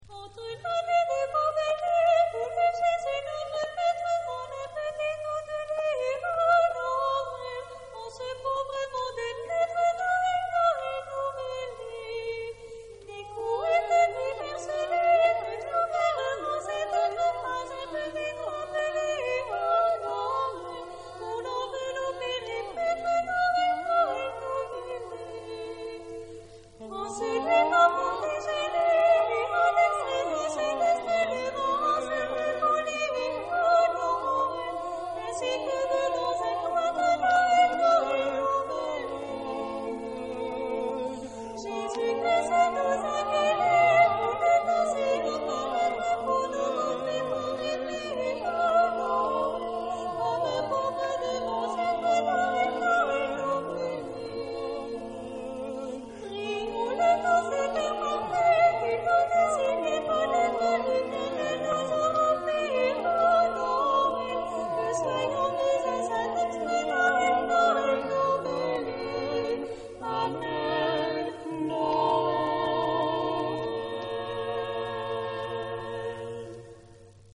Genre-Style-Forme : noël ; Profane ; contemporain
Type de choeur : SATB  (4 voix mixtes )
Solistes : Soprano (1)  (1 soliste(s))
Tonalité : la mineur